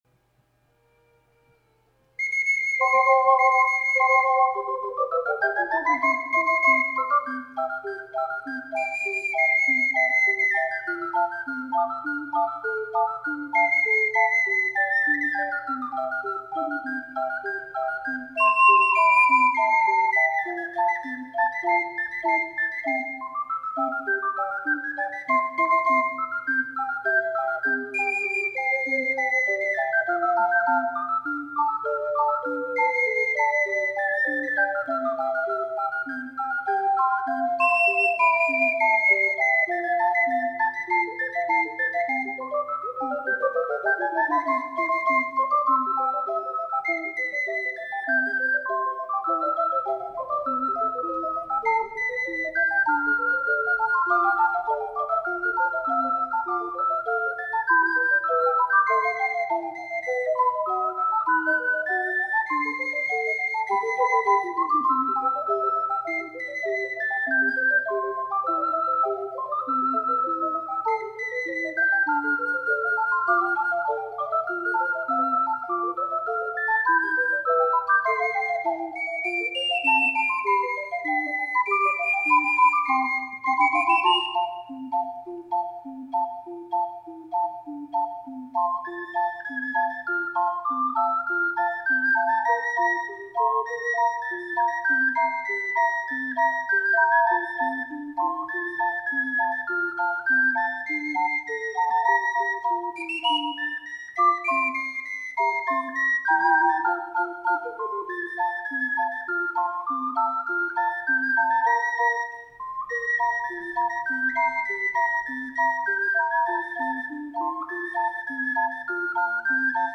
四重奏
リズミカルで楽しい曲です。
指定通りの速さで吹いたら、やや苦戦しましたが(^^;)。